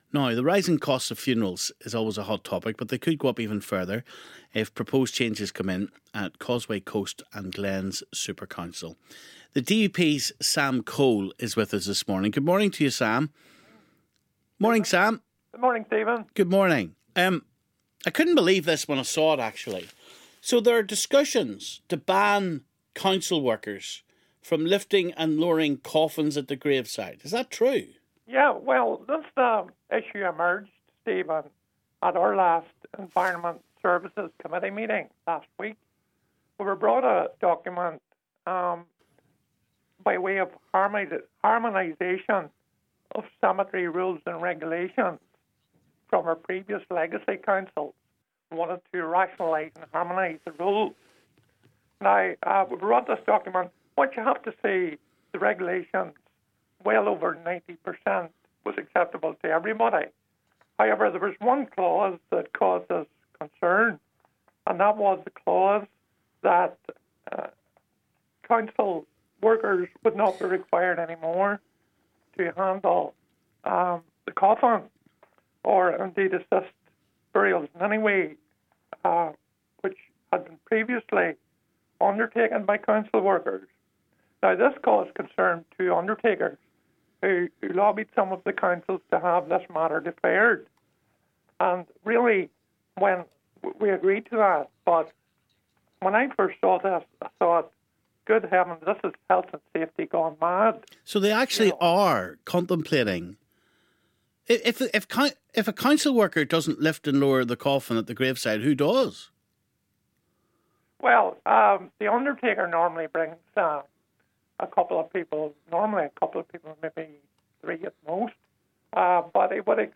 The rising costs of funerals is always a hot topic but they could go up even further if proposed changes come in at Causeway Coast and Glens Supercouncil. The DUP's Sam Cole says plans are being discussed to ban council workers from lifting and lowering coffins at the graveside after an employee took a case against the council when he hurt himself. He says it's health and safety gone mad...he's on the line.